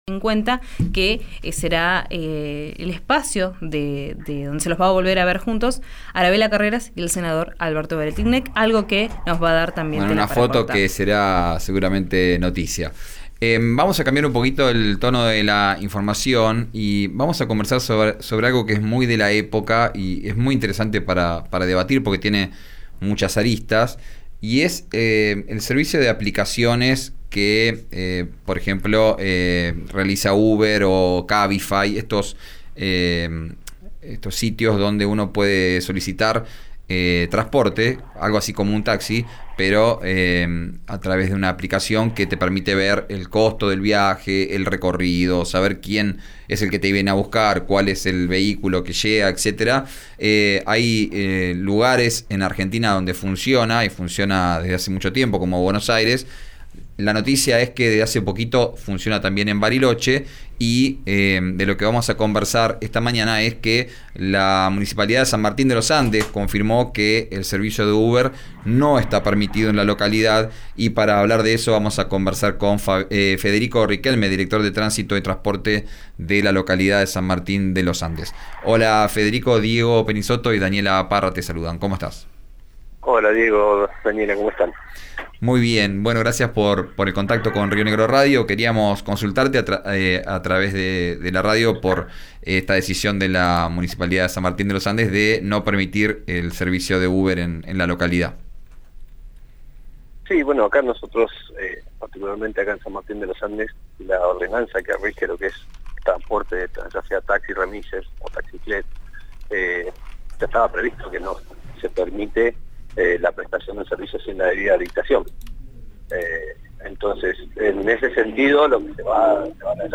Desde el Municipio desmintieron a la empresa y ratificaron cuáles son los servicios de transporte habilitados. Escuchá al director de Tránsito y Transporte de la localidad, Federico Riquelme, en RÍO NEGRO RADIO.